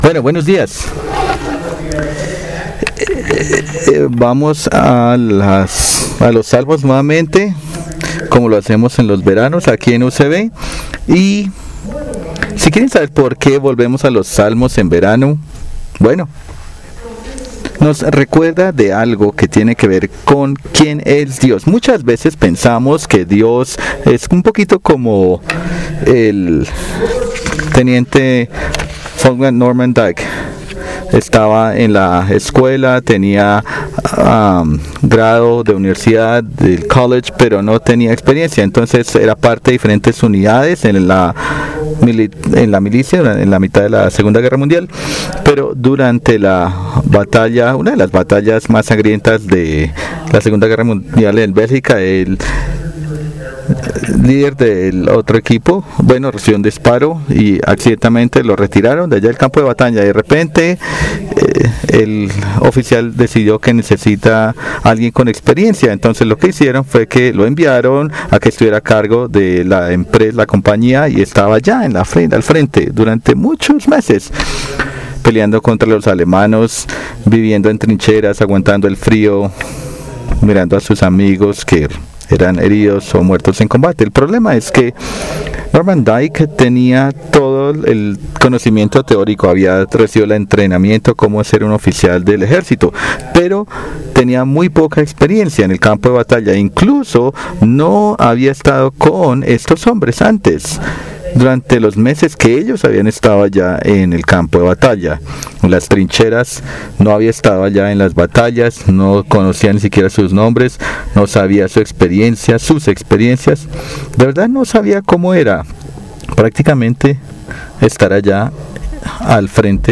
Spanish Sermons